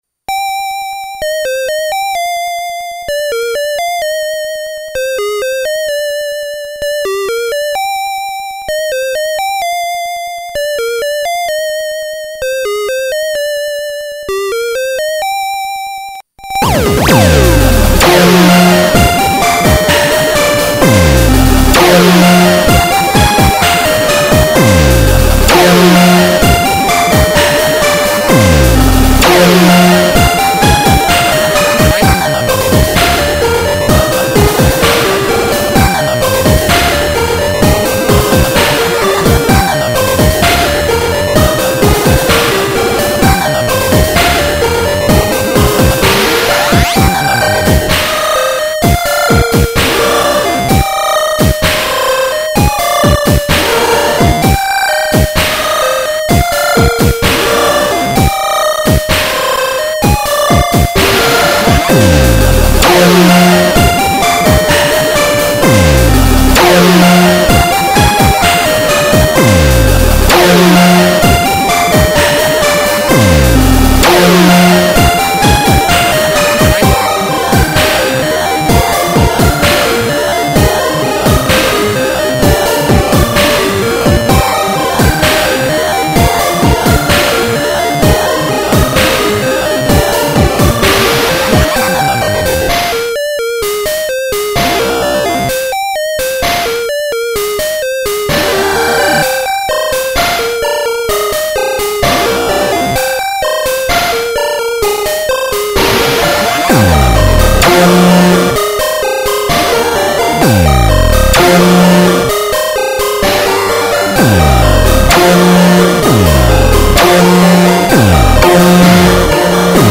My 2nd Chipstep, this took 2 Gameboys to make